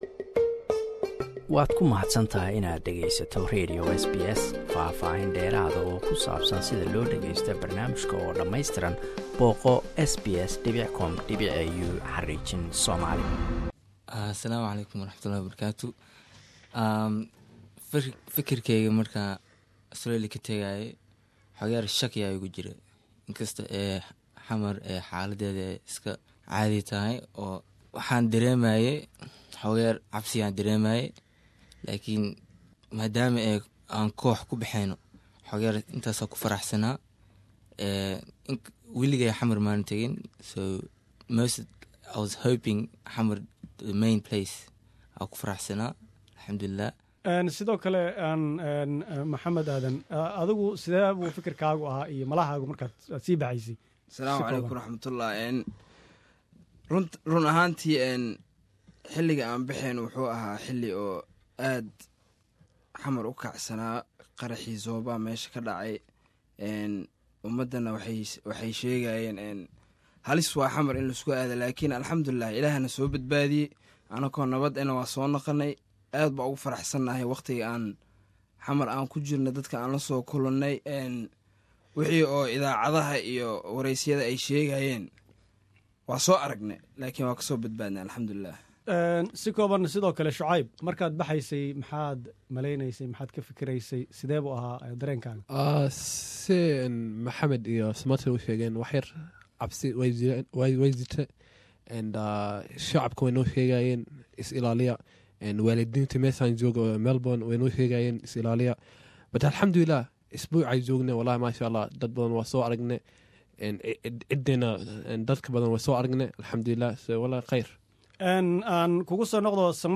Qaar kamida Kooxdii kubada cagta ee Jaaliyada Soomaalida Melbourne, ee Soomaaliya aaday, oon waraysi la yeelanay, iyagoo soo laabtay.